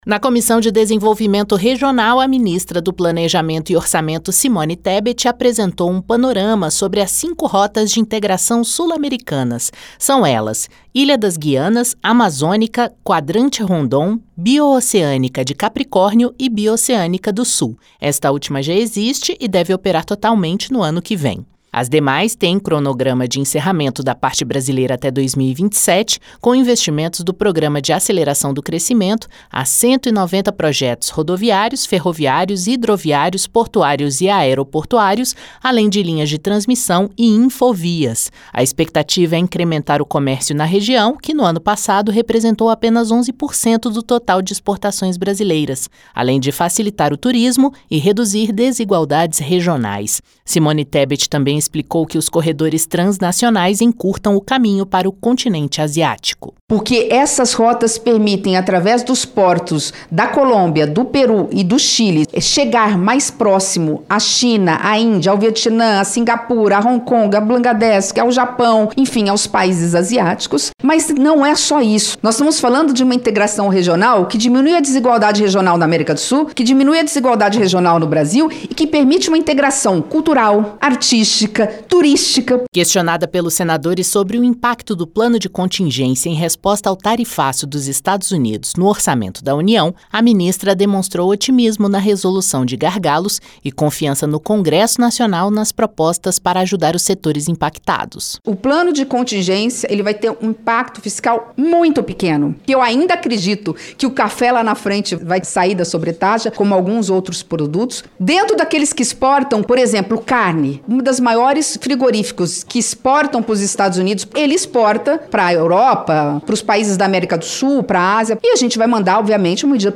Na Comissão de Desenvolvimento Regional e Turismo, a ministra do Planejamento e Orçamento, Simone Tebet, afirmou que as rotas de integração sul-americanas têm cronograma de encerramento da parte brasileira das obras entre 2025 e 2027, com investimentos do Programa de Aceleração do Crescimento (PAC).